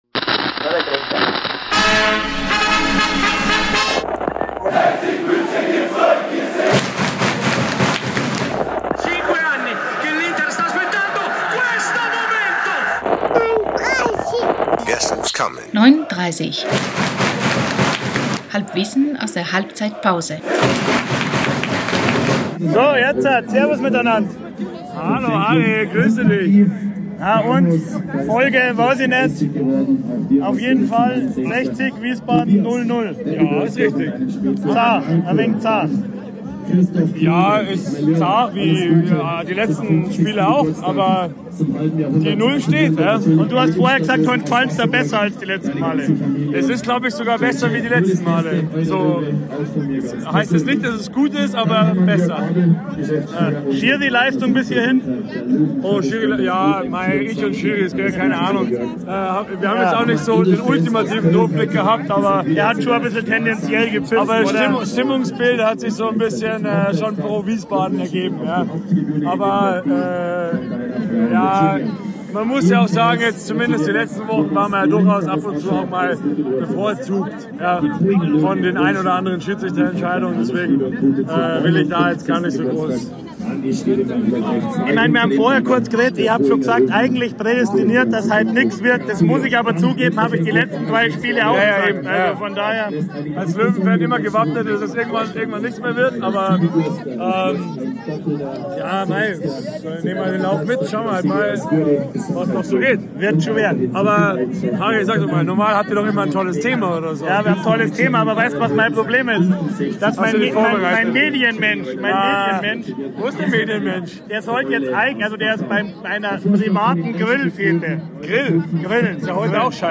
Der Podcast aus der Westkurve im Grünwalderstadion bei den Spielen des TSV 1860.